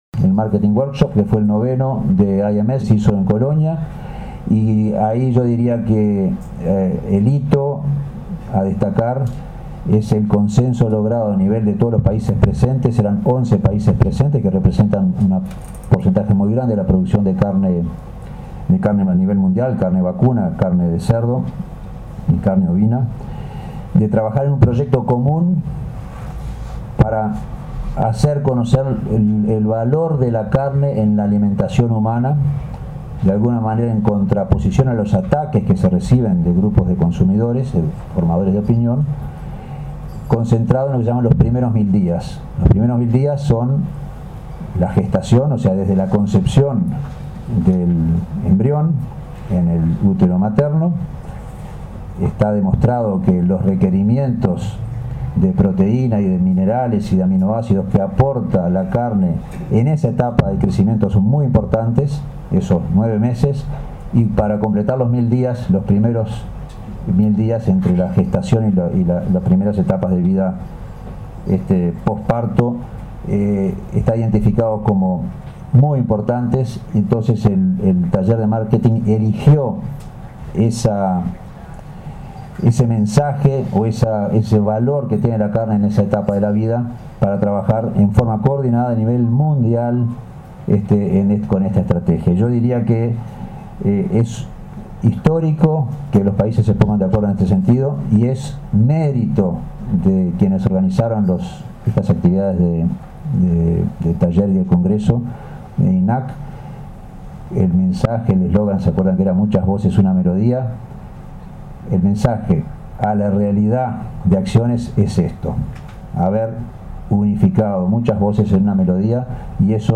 En la conferencia de prensa de cierre del 2016 el Presidente Federico Stanham destacó el proyecto internacional “los primeros 1000 días” que surgió en nuestro país durante el 9° Marketing Workshop de IMS desarrollado en Colonia de Sacramento en noviembre pasado.
Audio Presidente Federico Stanham